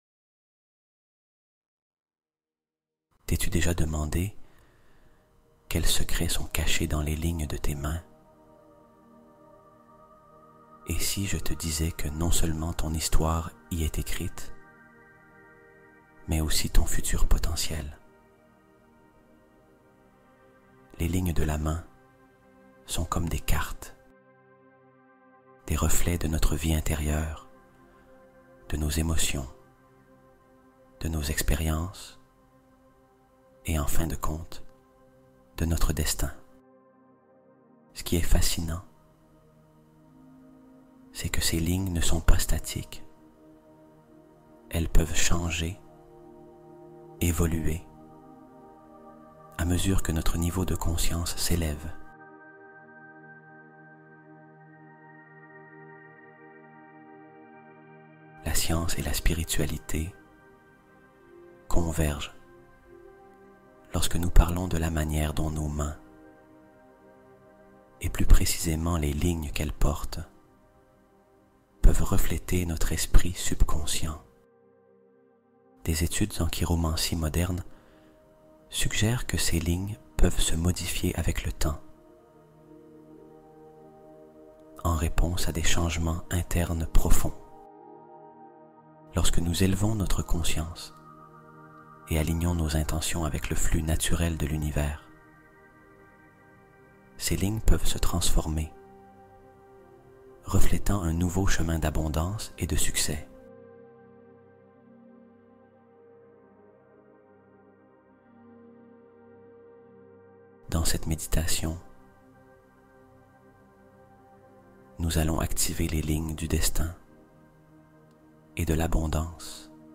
Affirmations nocturnes guidées : cultiver une prospérité stable